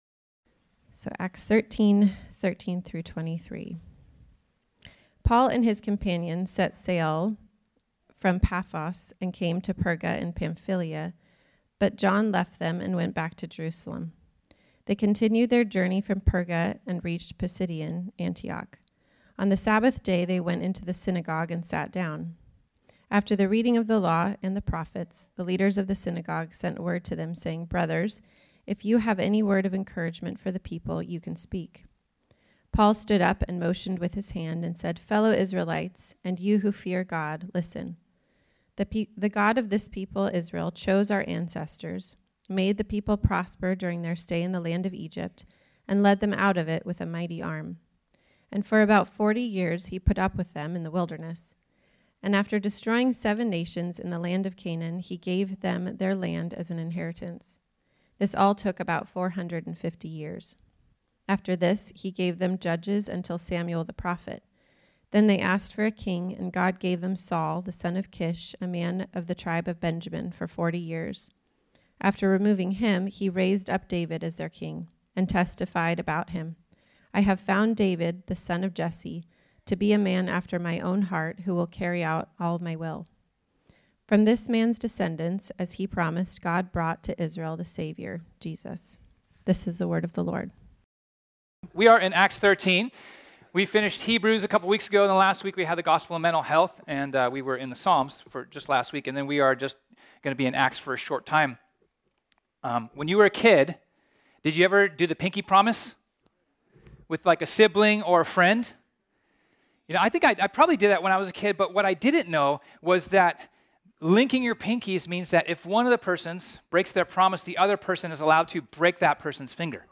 This sermon was originally preached on Sunday, April 2, 2023.